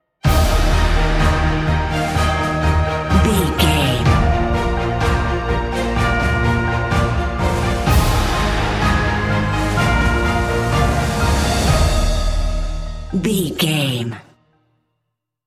Uplifting
Ionian/Major
D♯
Slow
epic
brass
cello
choir
orchestra
percussion
piano
strings
synthesizer
trumpet
violin